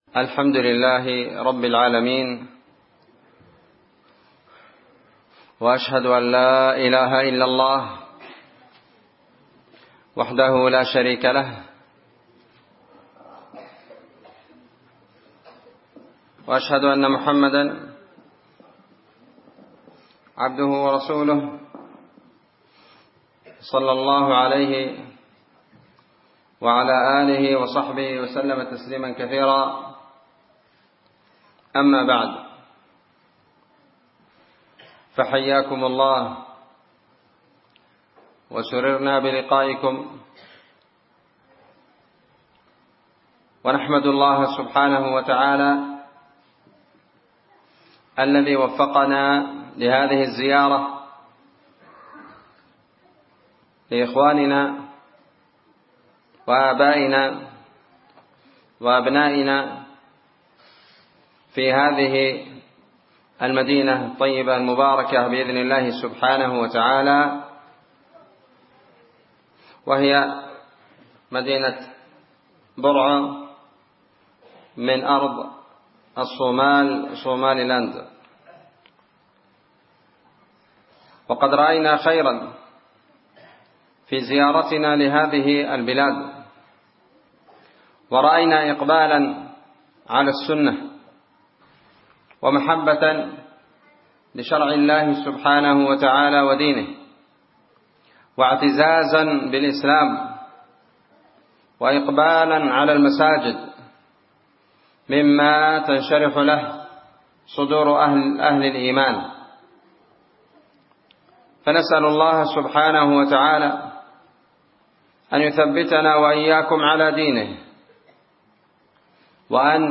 محاضرة بعنوان شرح حديث في الرؤى وأقسامها ليلة 16 جمادى الآخرة 1444